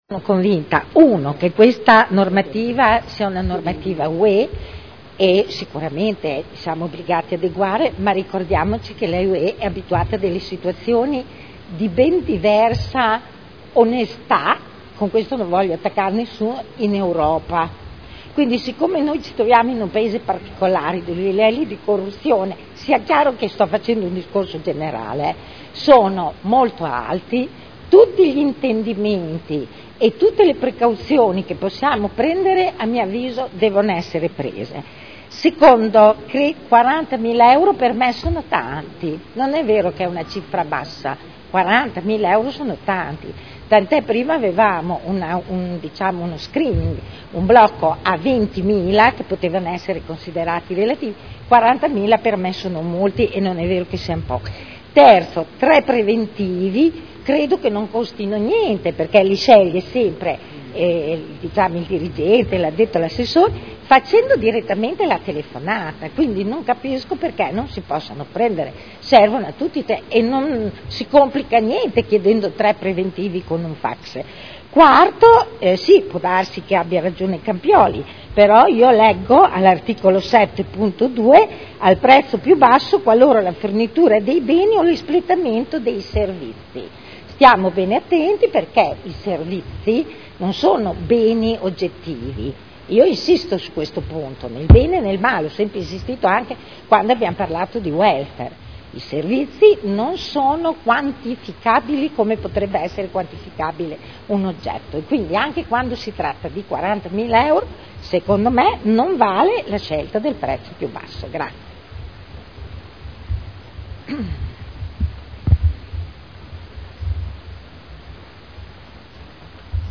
Dichiarazione di voto.